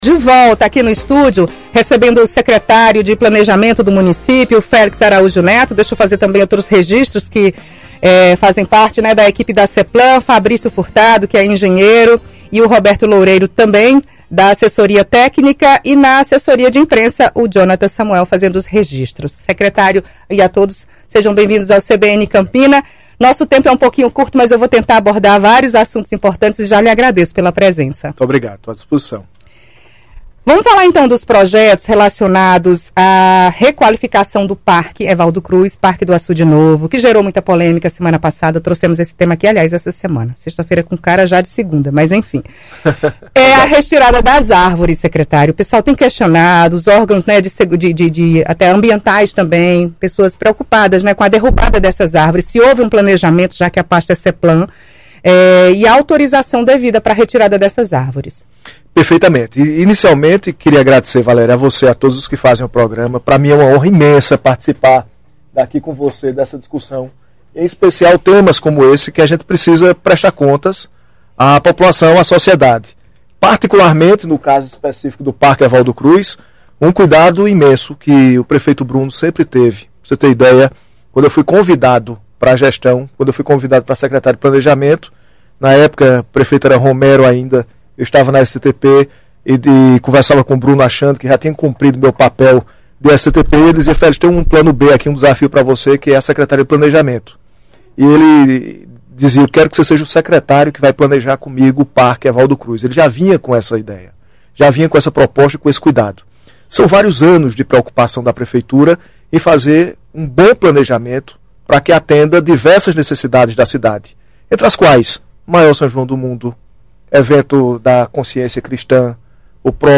CBN Campina: secretário de planejamento explica andamento de obras em Campina – CBN Paraíba
Em entrevista ao CBN Campina desta sexta-feira, 08, o secretário de planejamento do município, Félix Araújo Neto, falou sobre os projetos executados p ela pasta em Campina Grande.